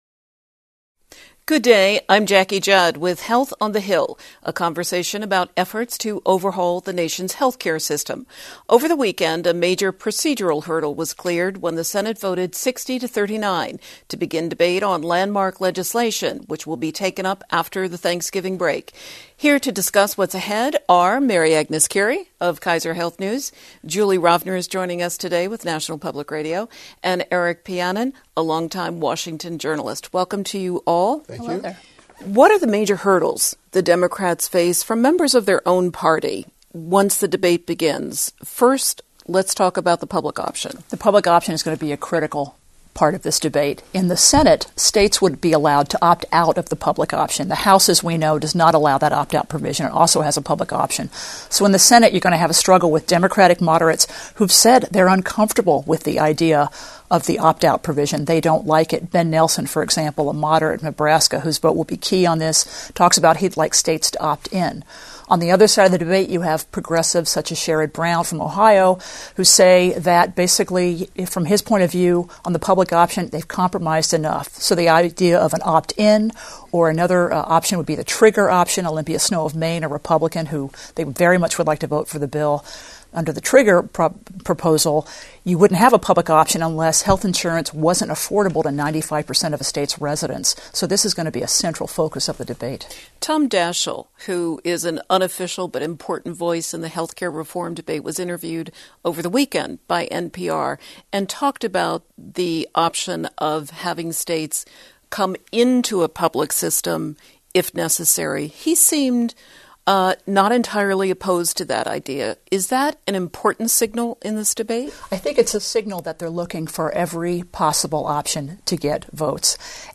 Panelists discuss the Senate's vote to begin debate on health reform legislation.